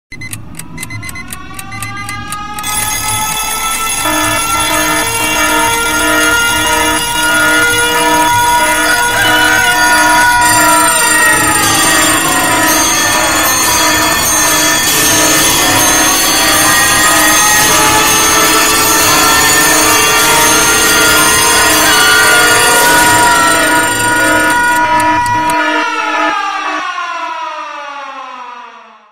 Kategorien Wecktöne